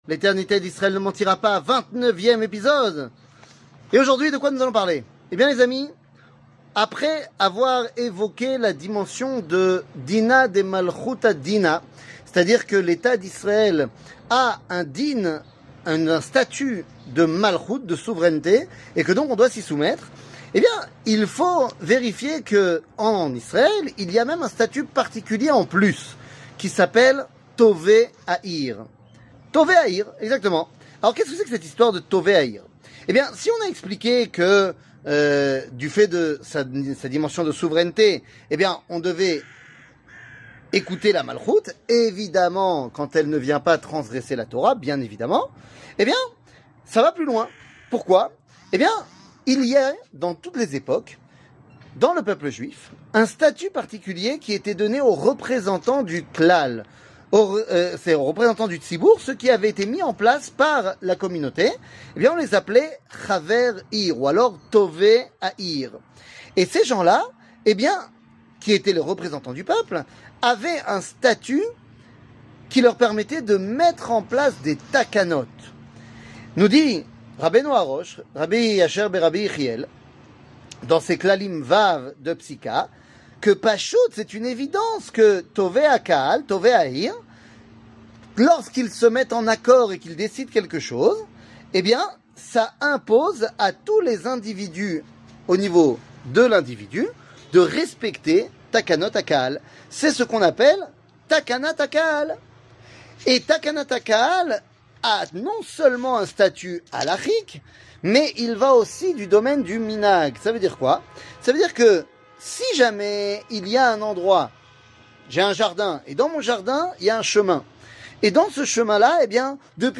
L'éternité d'Israel ne mentira pas ! 29 00:05:22 L'éternité d'Israel ne mentira pas ! 29 שיעור מ 15 נובמבר 2023 05MIN הורדה בקובץ אודיו MP3 (4.92 Mo) הורדה בקובץ וידאו MP4 (9.51 Mo) TAGS : שיעורים קצרים